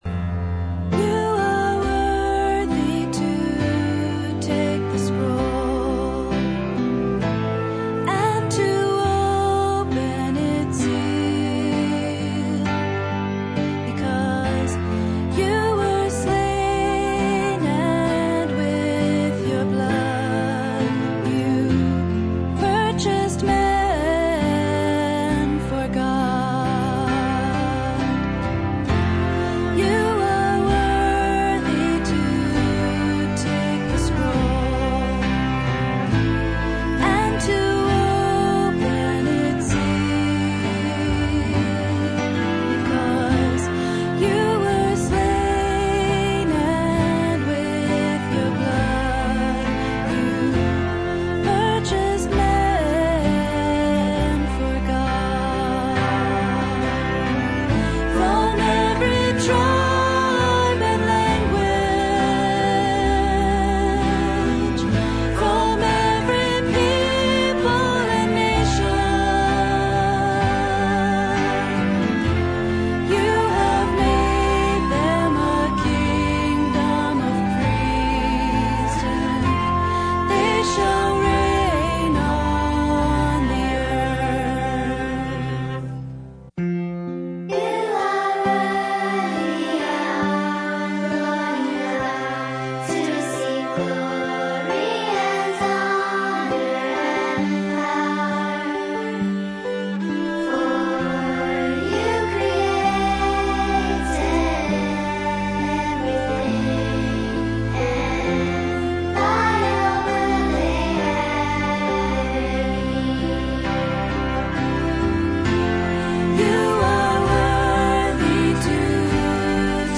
Revelation 5:9, Mixed Choirs